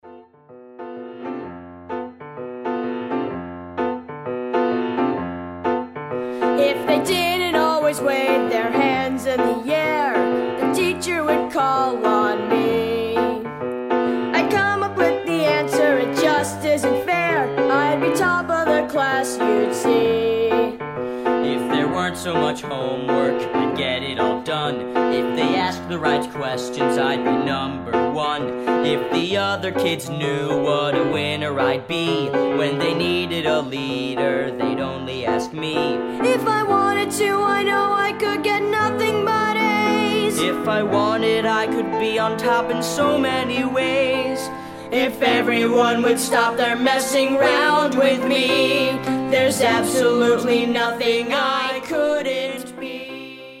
The music was recorded at The Audio Workshop.